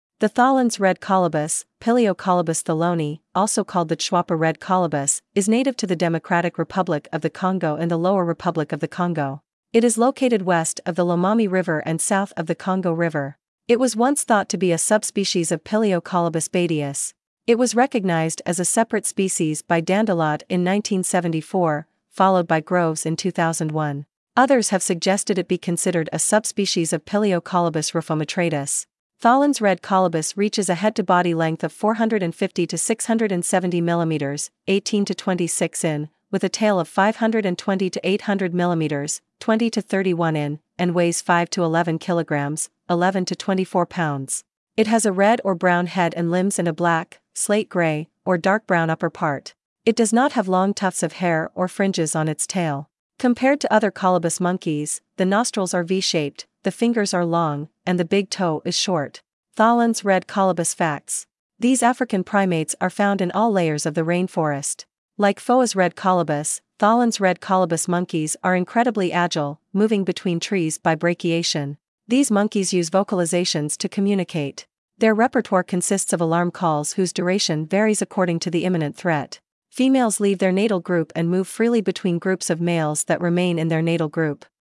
Their repertoire consists of alarm calls whose duration varies according to the imminent threat.
Thollons-red-colobus.mp3